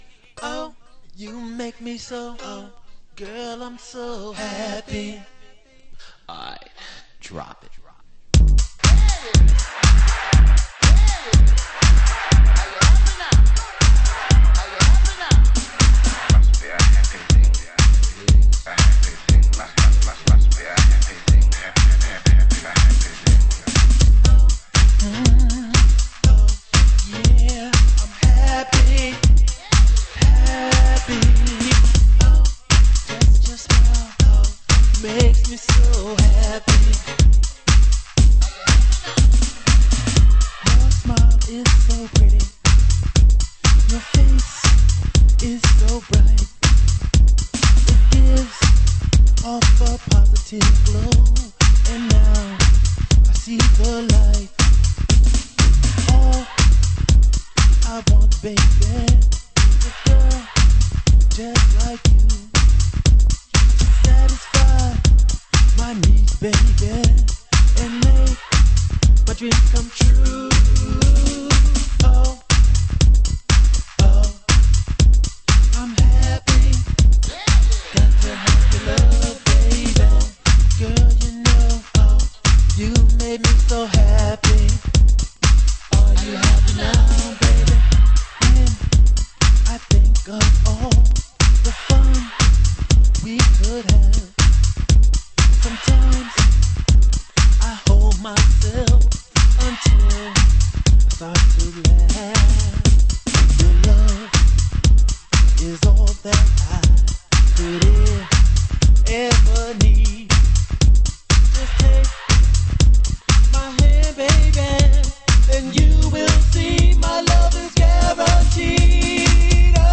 HOUSE MUSIC
盤質：盤面綺麗ですがチリノイズ有/濃茶クリア盤